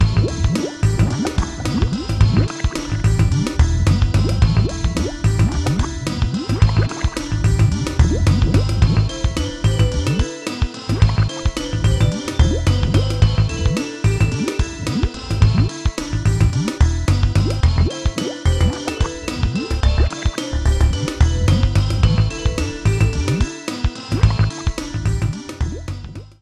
Edited Clipped to 30 seconds and applied fade-out.